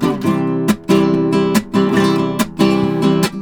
Index of /90_sSampleCDs/USB Soundscan vol.59 - Spanish And Gypsy Traditions [AKAI] 1CD/Partition B/04-70C RUMBA